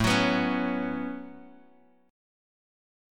A6add9 chord